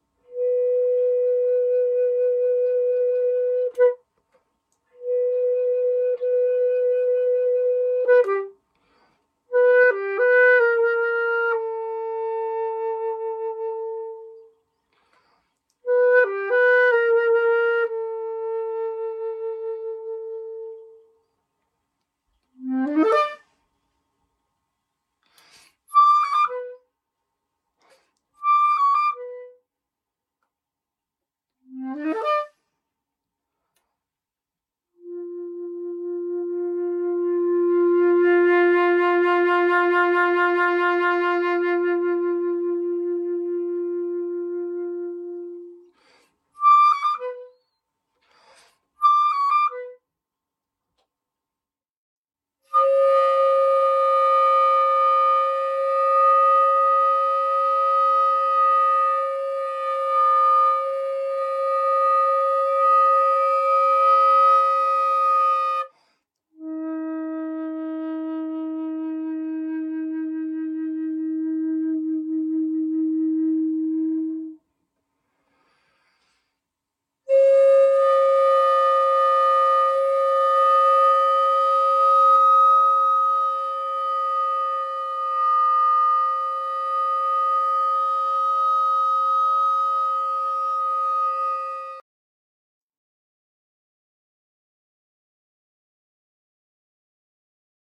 Pièce pour flûte seule